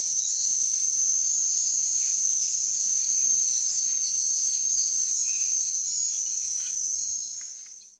Rainforest Ambience
Dense tropical rainforest with layered insect chorus, distant bird calls, and dripping canopy
rainforest-ambience.mp3